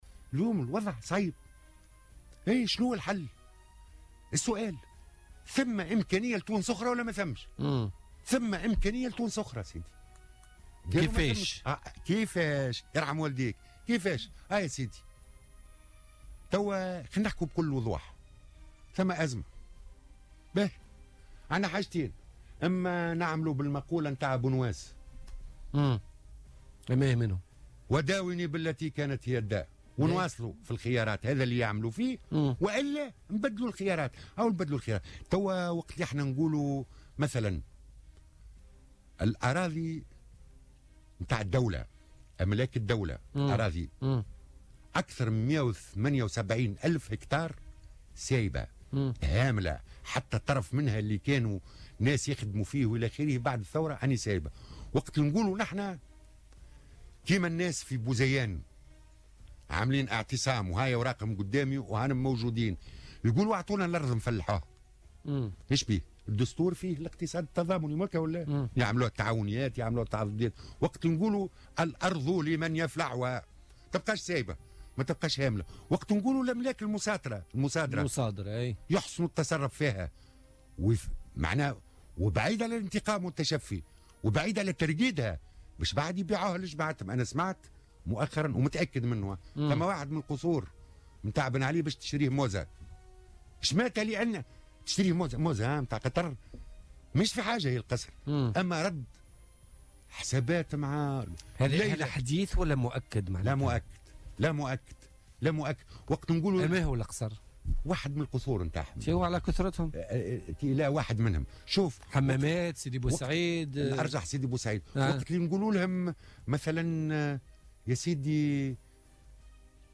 قال القيادي في الجبهة الشعبية عمار عمروسية، خلال استضافته اليوم في برنامج "بوليتكا"، إن الحكومة تخطّط لبيع احد قصور الرئيس الأسبق زين العابدين بن علي، للشيخة موزا زوجة أمير قطر، مضيفا أنه من المرجّح أن يكون قصر سيدي بوسعيد.